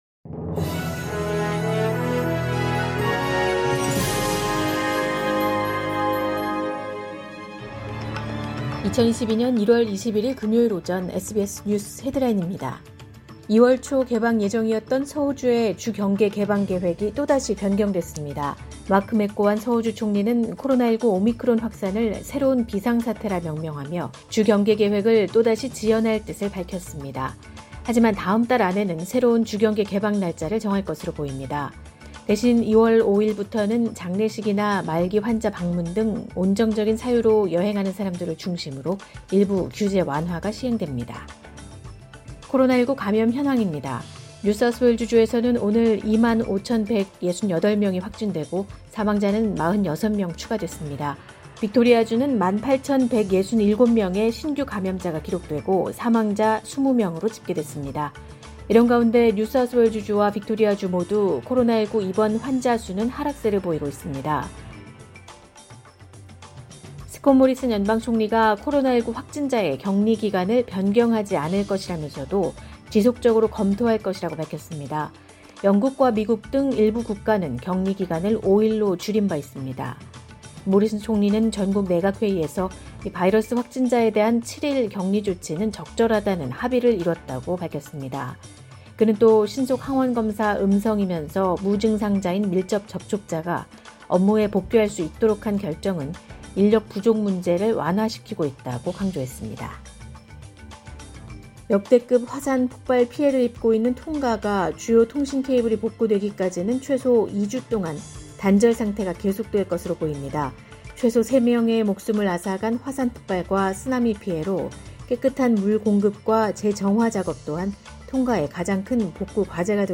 2022년 1월 21일 금요일 오전의 SBS 뉴스 헤드라인입니다.